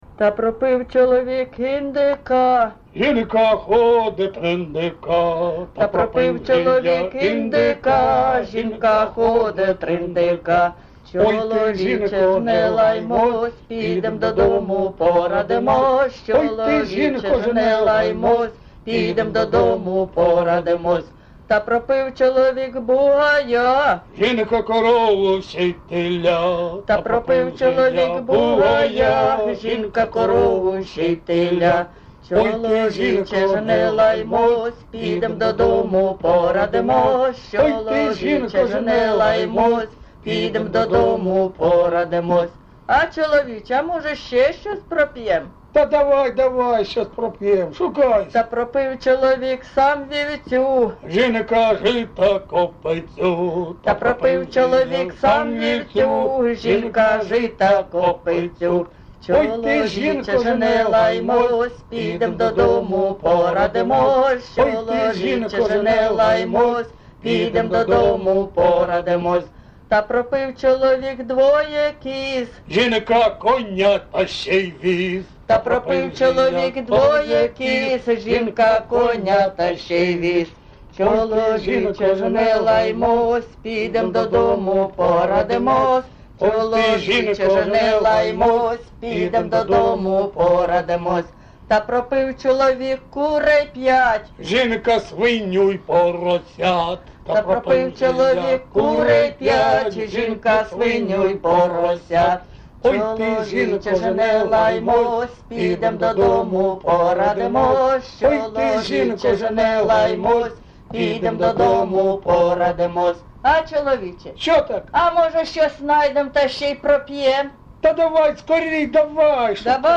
ЖанрЖартівливі
Місце записус-ще Красноріченське, Кремінський район, Луганська обл., Україна, Слобожанщина